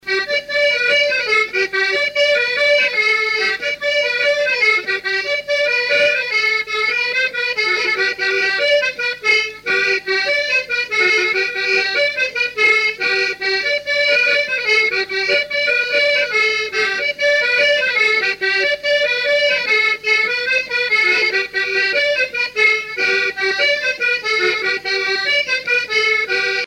Couplets à danser
branle : courante, maraîchine
musique à danser à l'accordéon diatonique
Pièce musicale inédite